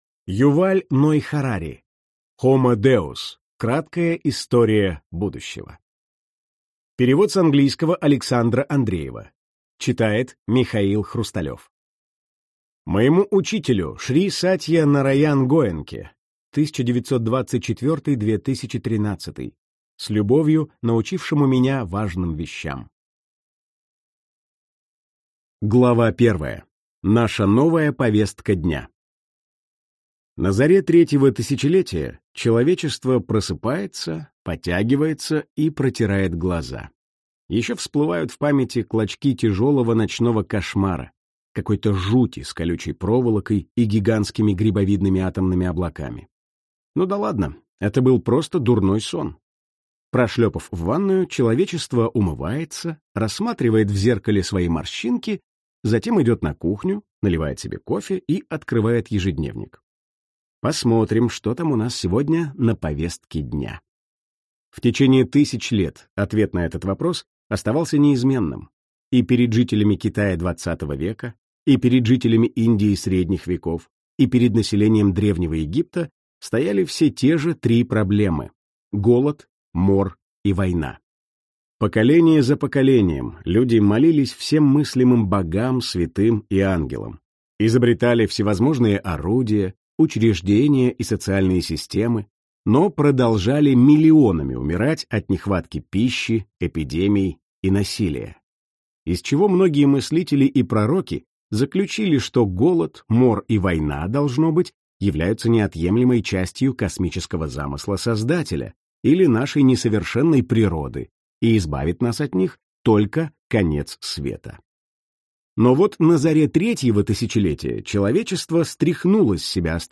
Аудиокнига Homo Deus. Краткая история будущего | Библиотека аудиокниг